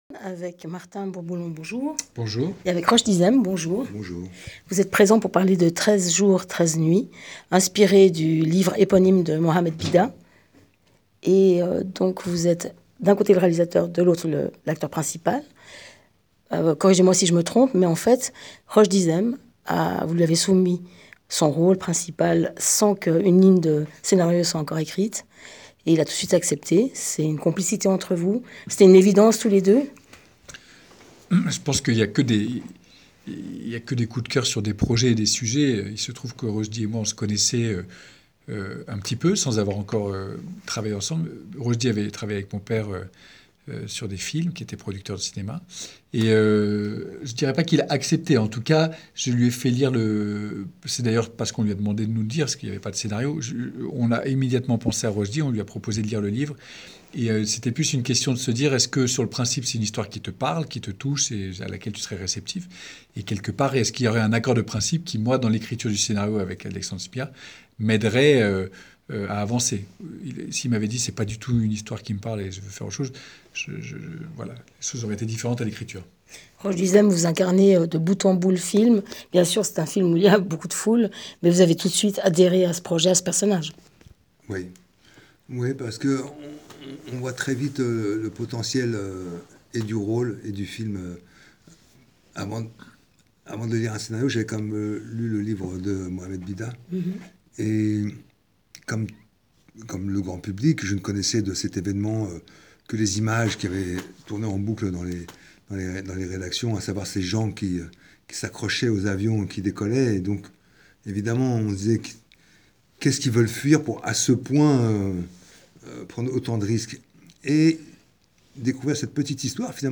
Avec 13 jours, 13 nuits, Martin Bourboulon porte sur grand écran un acte héroïque méconnu de l’histoire contemporaine à travers un thriller haletant. Rencontre avec Martin Bourboulon et Roschdy Zem - j:mag